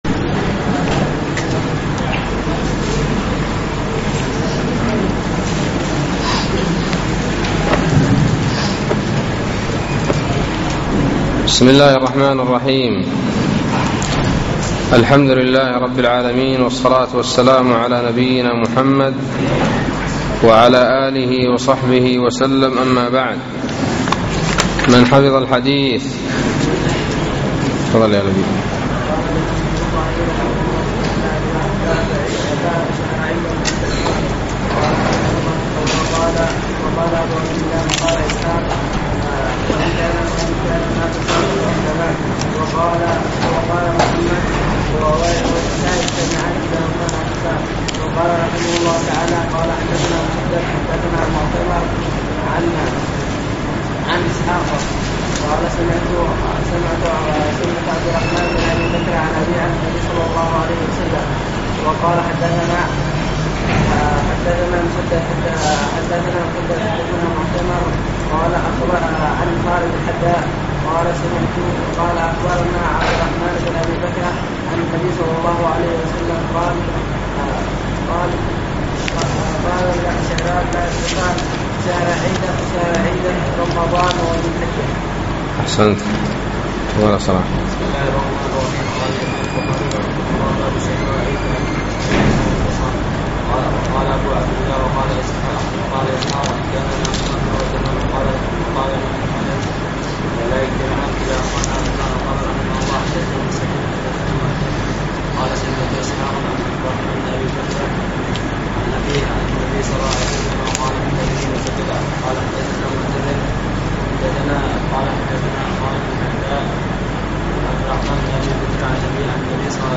الدرس الحادي عشر : باب قول النبي صلى الله عليه وسلم (لا نكتب ولا نحسب)